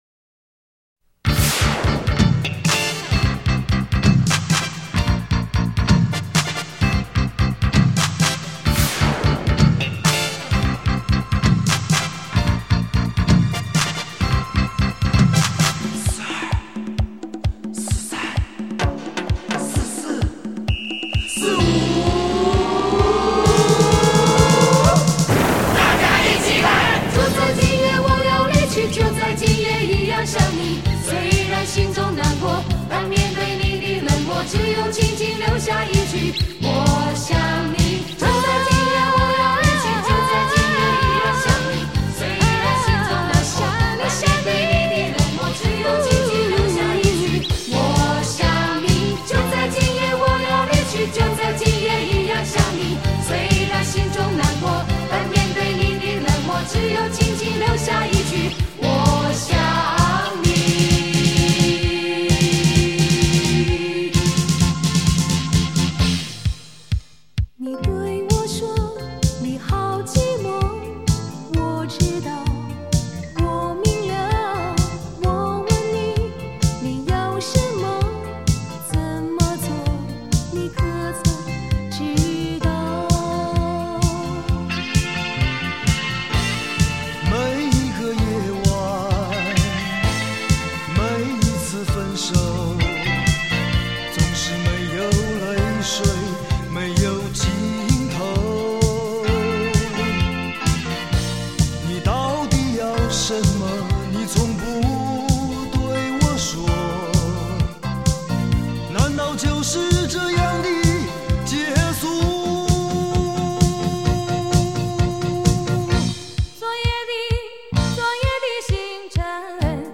港台流行金曲大联唱